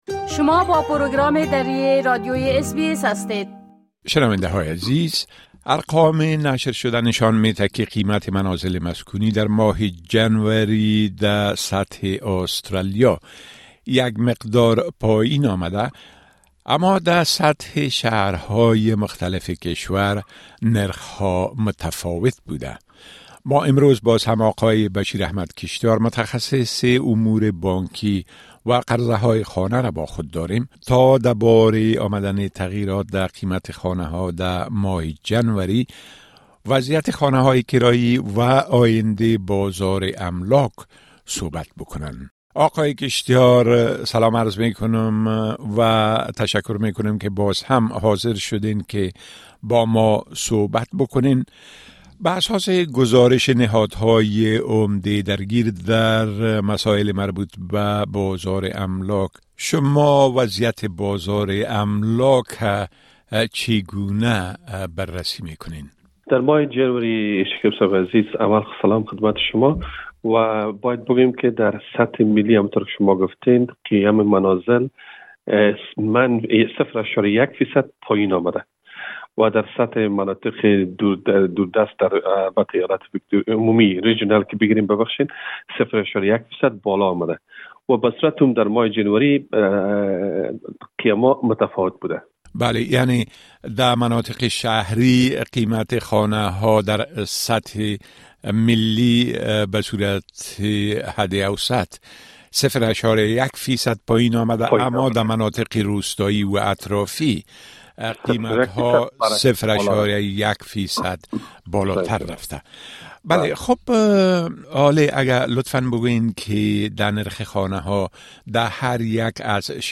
بازار املاک: نوسانات در قیمت و کرایه‌ خانه‌ها؛ گفتگو